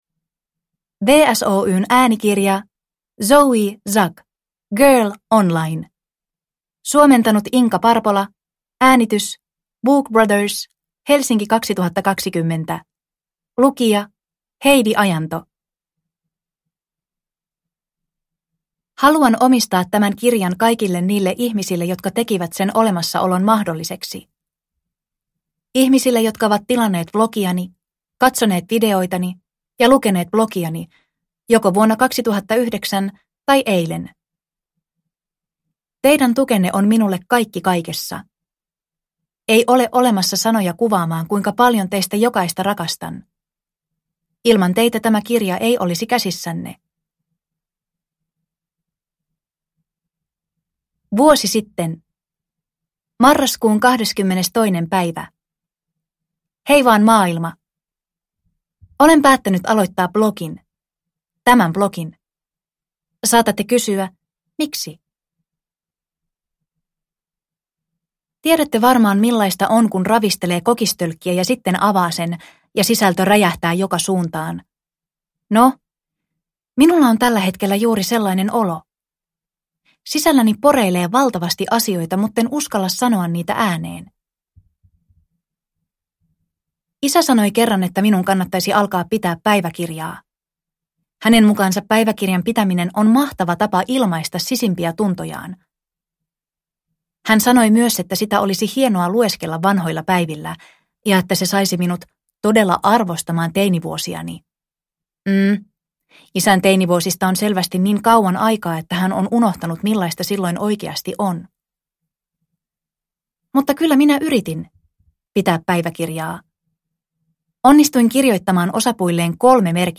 Girl Online – Ljudbok – Laddas ner